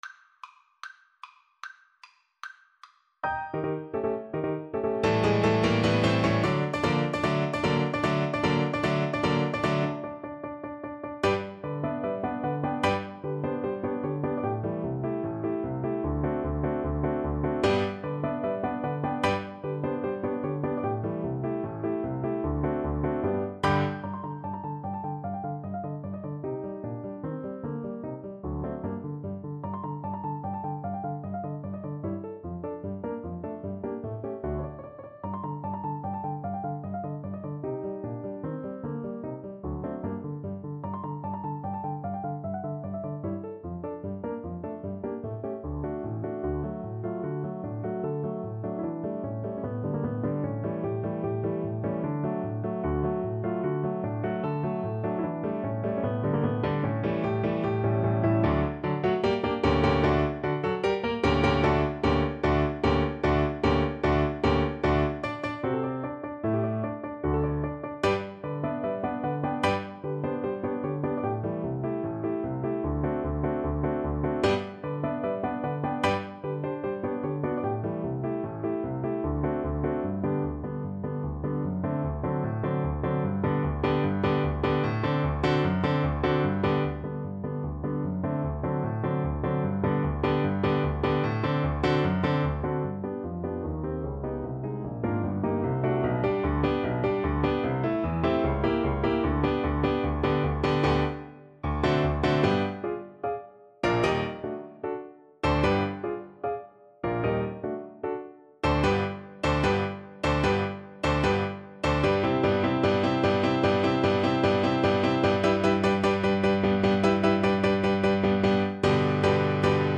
Play (or use space bar on your keyboard) Pause Music Playalong - Piano Accompaniment Playalong Band Accompaniment not yet available transpose reset tempo print settings full screen
2/4 (View more 2/4 Music)
G major (Sounding Pitch) (View more G major Music for Cello )
Allegro vivacissimo ~ = 150 (View more music marked Allegro)
Classical (View more Classical Cello Music)